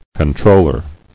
[con·trol·ler]
Con*trol"ler*ship, n. The office of a controller.